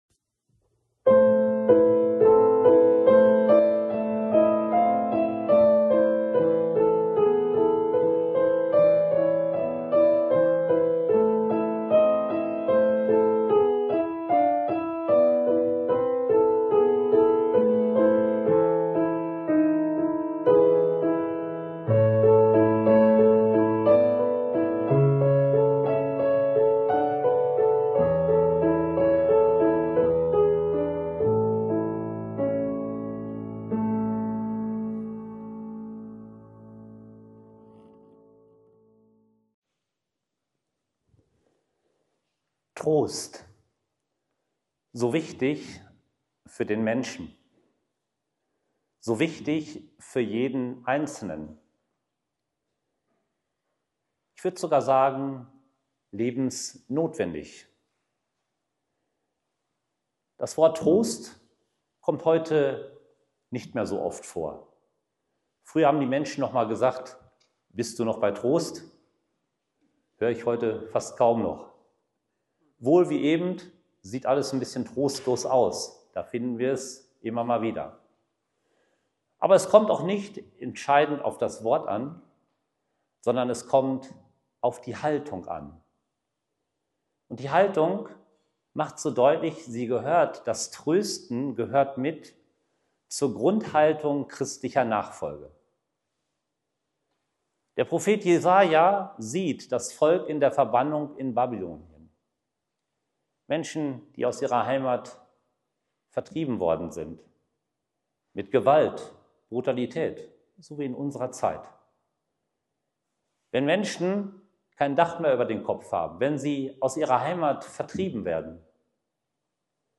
Um diese Impulse und andere ging es in der Agapefeier im Oktober.